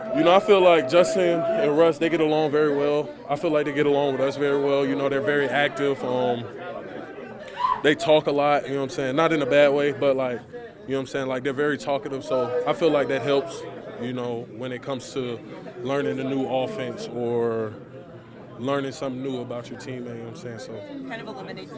Tackle Broderick Jones said Wilson and Fields have made learning new things easier for him.